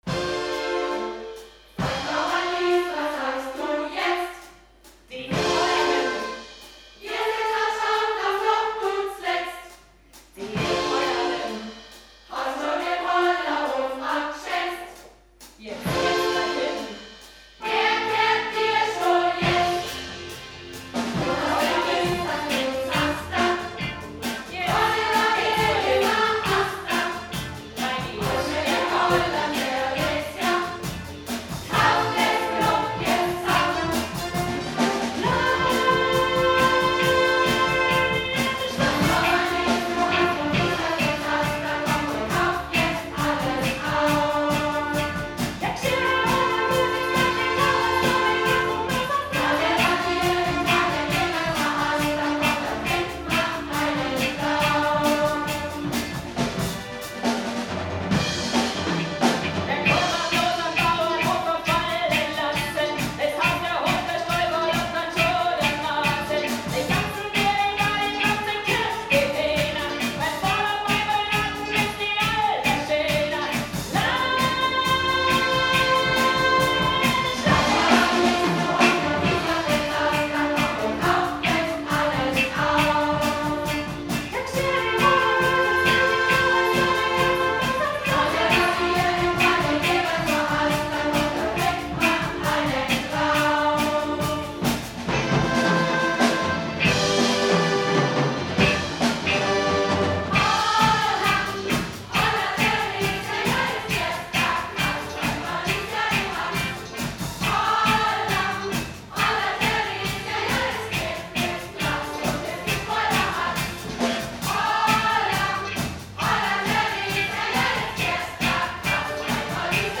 ein bairisches Musical
Der Prolog wird von dem Dreig’sang beendet, der die Zuschauer auffordert, dem weiteren Gang der Dinge zu folgen.
Zu den Klängen der Alphörner treffen die Gemeindemitglieder zum Leichenschmaus im Wirtshaus ein.